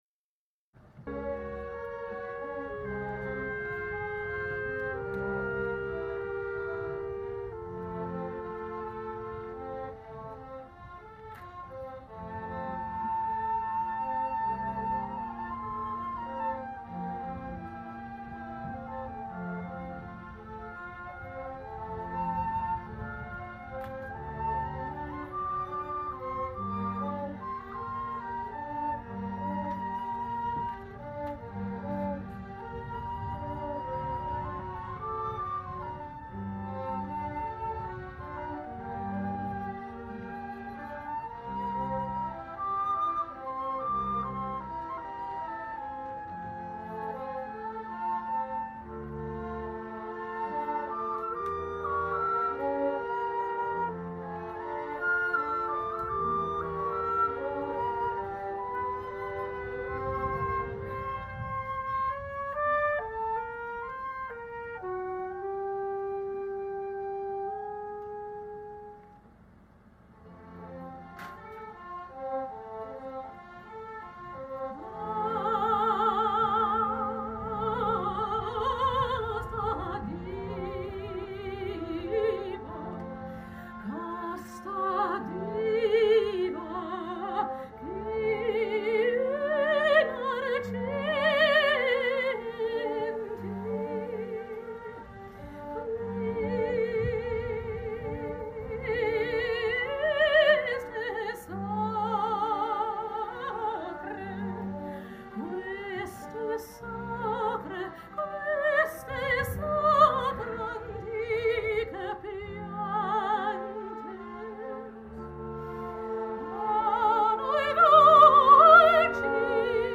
Wallufer Männerchöre, und
Frauen (siehe bei Beteiligte)